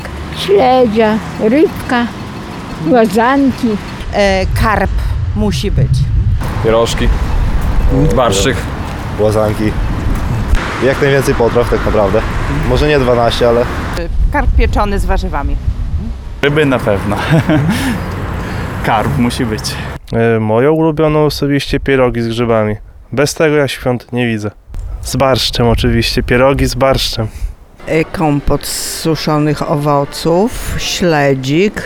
O to, jakie dania pojawią się na wigilijnym stole w domach przechodniów, zapytaliśmy na ulicach miasta.